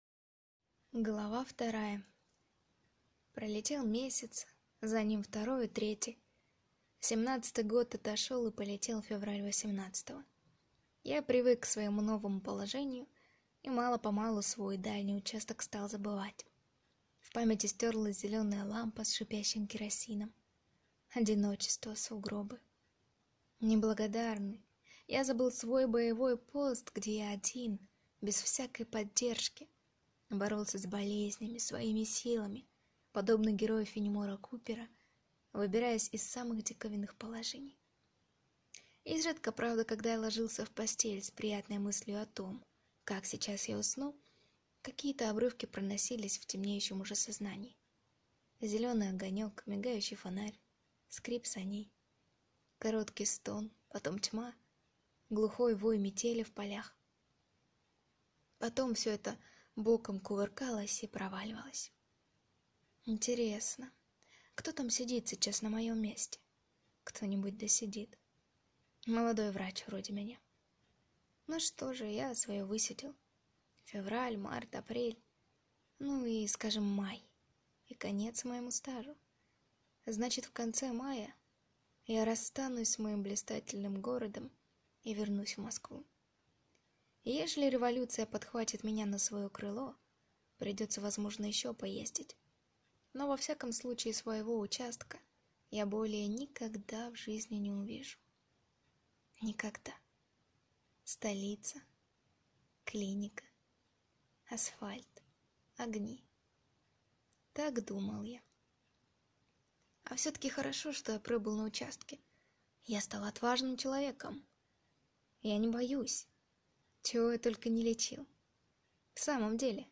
Аудиокнига О страстях и пороках (сборник) | Библиотека аудиокниг